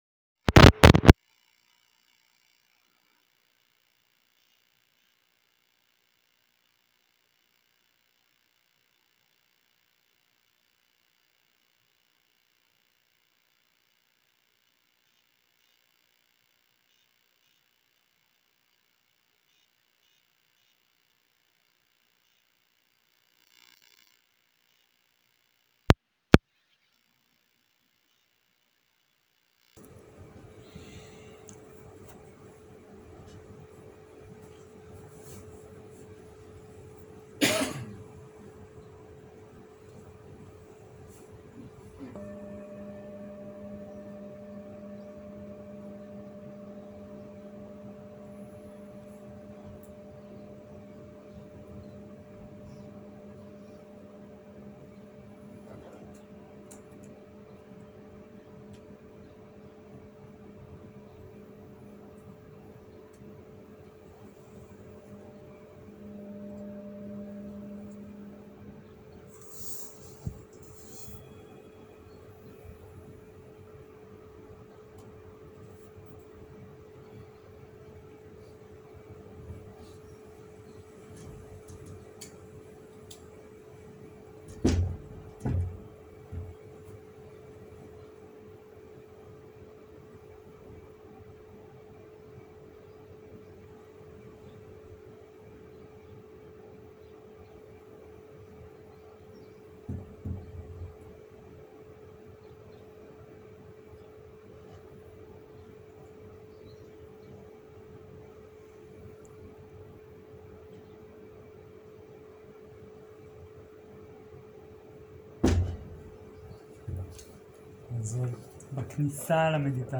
בוקר - הנחיות מדיטציה - מטא לדמות מיטיבה 05.06.2022